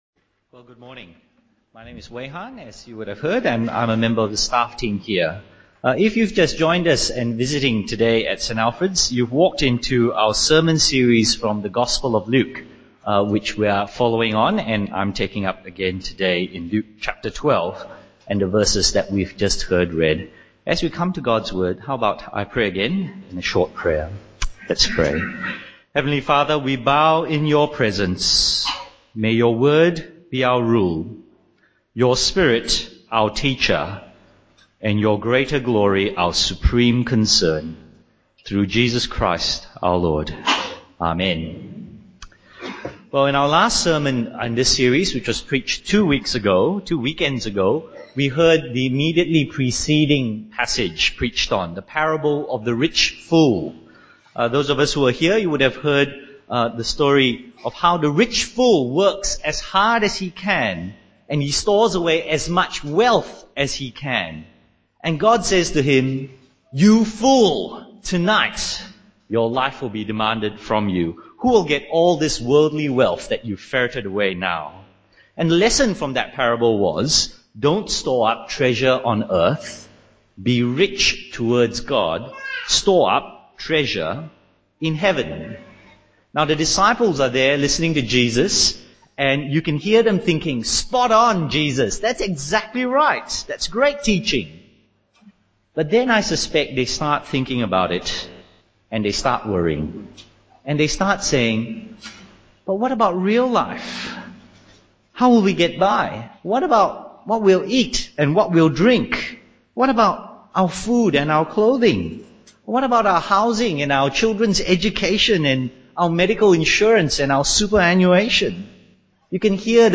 Download Download Bible Passage Luke 12:22-34 In this sermon